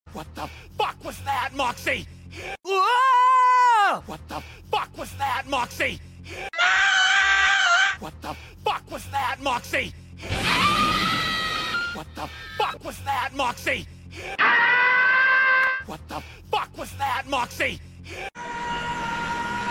Moxxie Screams Variations Sound Effects Free Download
funny sound effects on tiktok